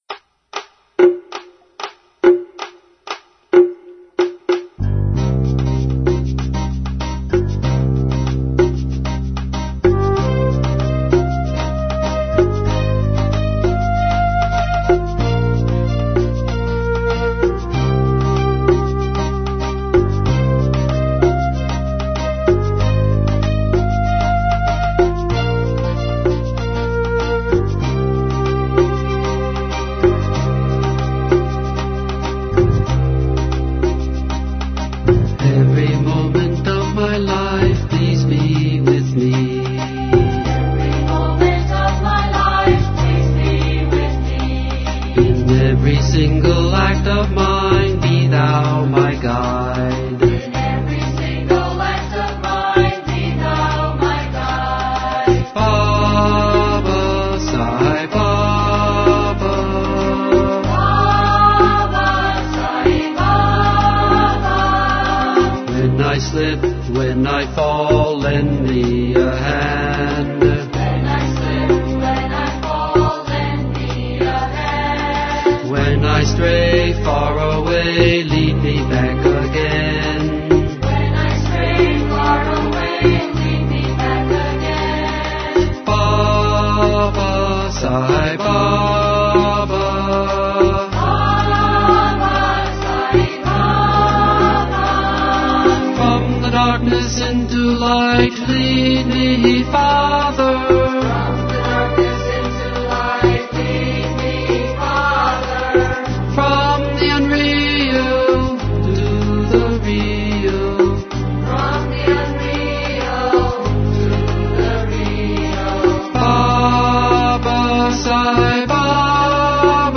1. Devotional Songs
Harmonic Minor 8 Beat  Men - 2 Pancham  Women - 6 Pancham
Harmonic Minor
8 Beat / Keherwa / Adi
Medium Fast
2 Pancham / D
6 Pancham / A
Lowest Note: n2 / B (lower octave)
Highest Note: G1 / E♭ (higher octave)